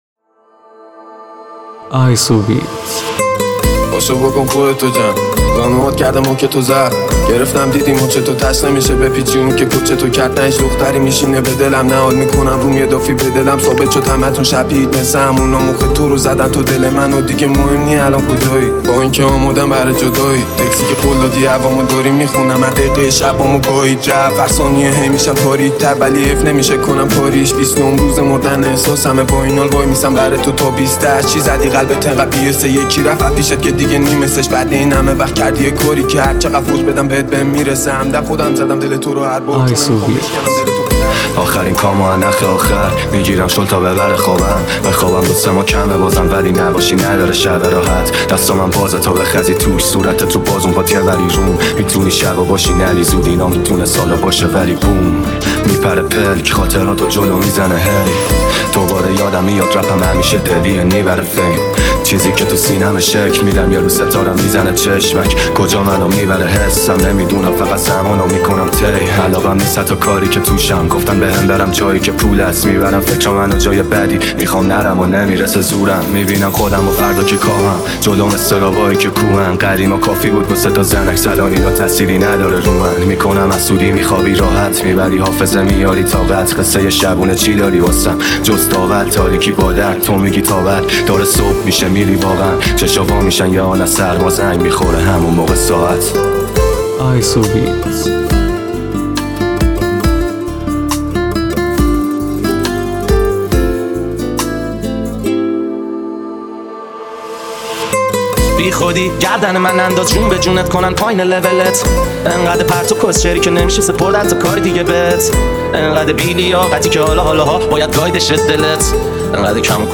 ریمیکس رپی
ریمیکس رپ خفن
ریمیکس بیس دار مخصوص ماشین